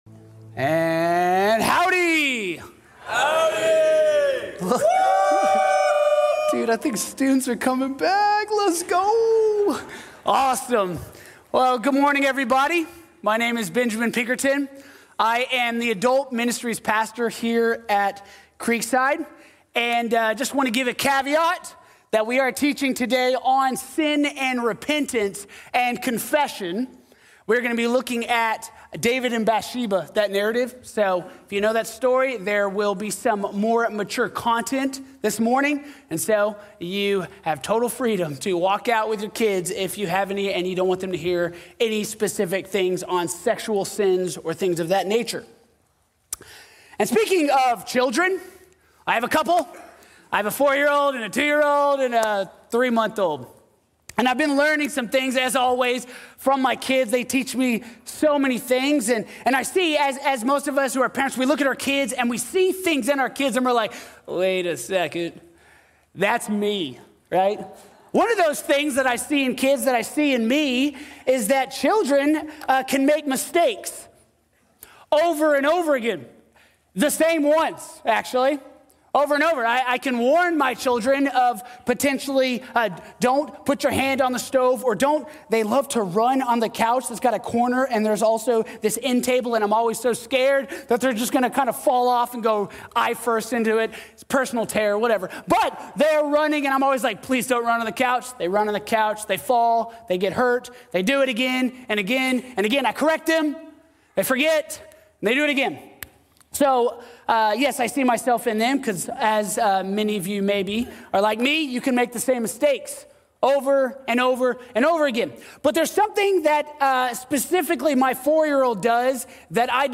El pecado, el arrepentimiento y el Dios que restaura | Sermón | Iglesia Bíblica de la Gracia